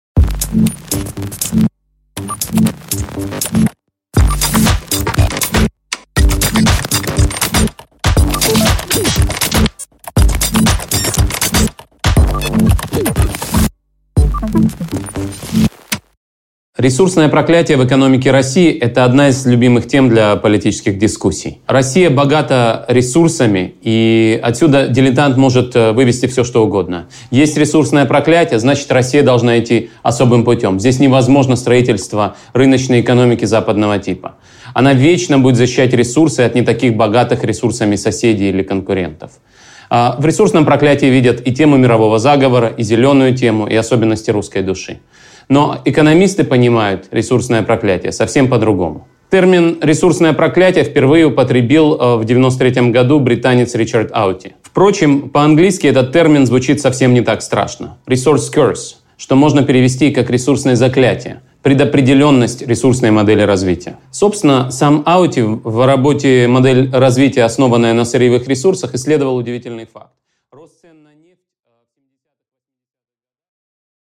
Аудиокнига Недра и закрома: экономика «ресурсного проклятия» | Библиотека аудиокниг